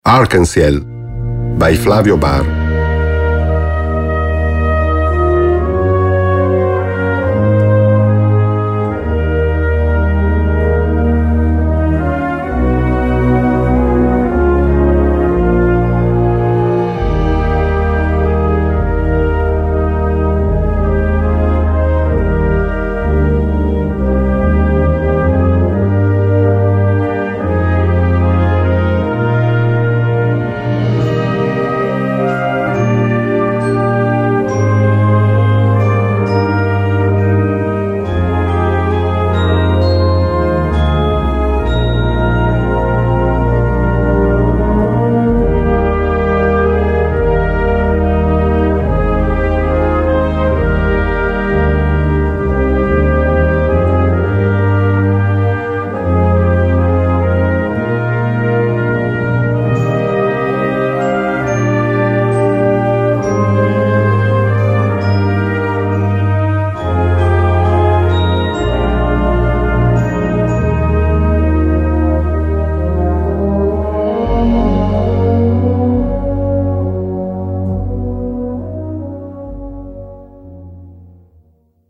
Gattung: Konzertwerk
4:27 Minuten Besetzung: Blasorchester PDF